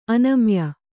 ♪ anamya